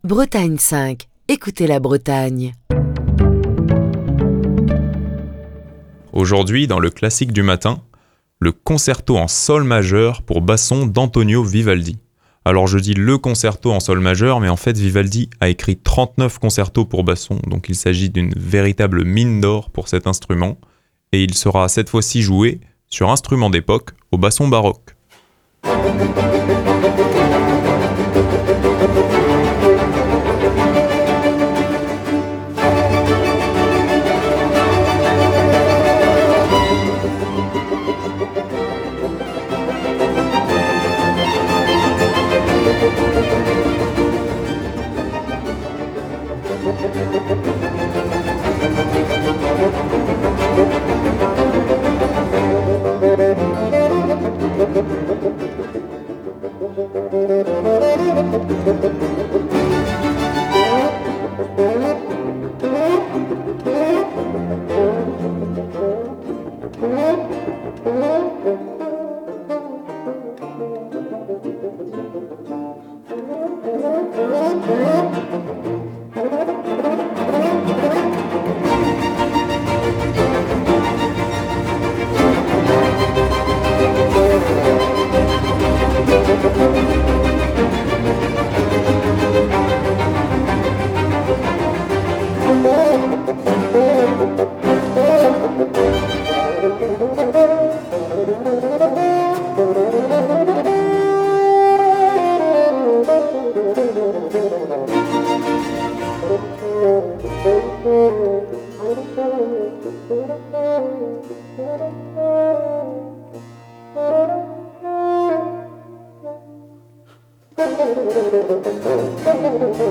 Concerto pour basson en Sol majeur
basson baroque